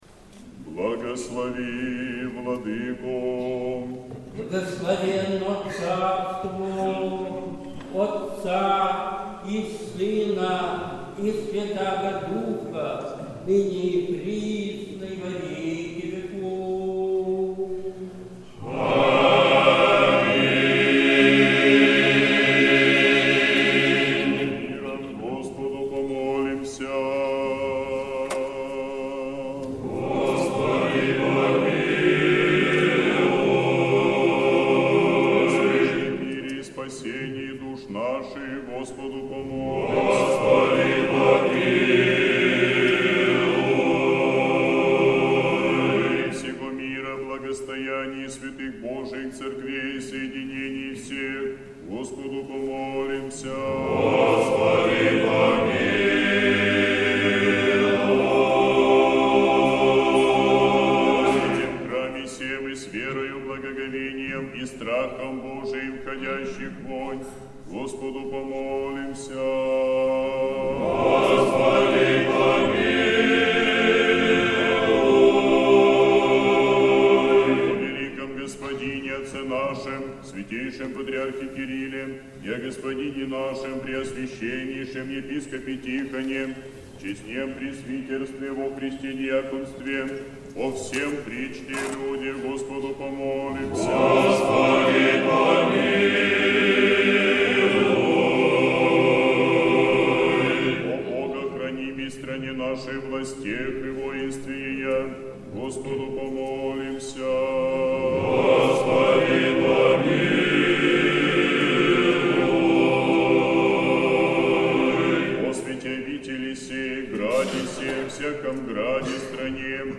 Божественная литургия. Хор Сретенского монастыря.
Божественная литургия в Сретенском монастыре в Неделю о мытаре и фарисее